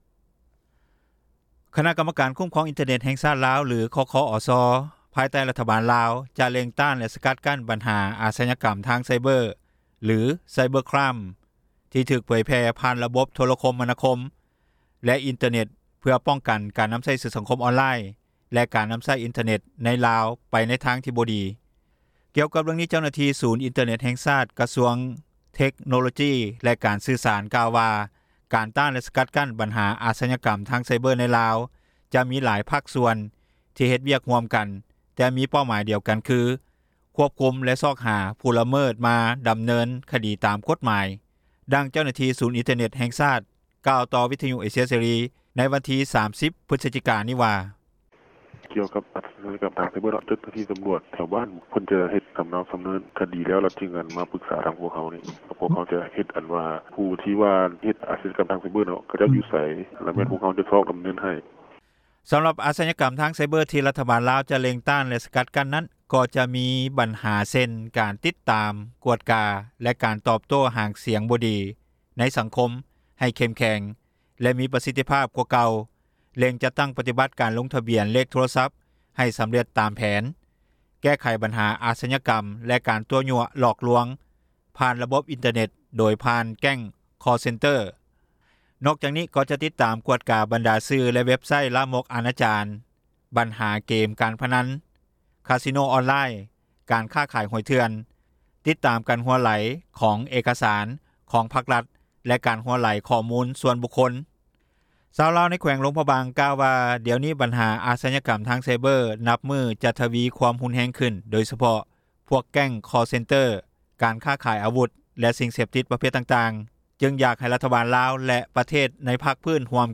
ດັ່ງເຈົ້າໜ້າທີ່ ສູນອິນເຕີເນັດ ແຫ່ງຊາຕ ກ່າວຕໍ່ວິທຍຸເອເຊັຽເສຣີ ໃນວັນທີ 30 ພຶສຈິການີ້ວ່າ:
ດັ່ງຊາວລາວ ແຂວງຊຽງເຊກອງ ກ່າວຕໍ່ວິທຍຸເອເຊັຽເສຣີ ໃນມື້ດຽວກັນວ່າ:
ດັ່ງຊາວລາວ ໃນນະຄອນຫຼວງວຽງຈັນ ກ່າວຕໍ່ວິທຍຸເອເຊັຽເສຣີ ໃນມື້ດຽວກັນວ່າ: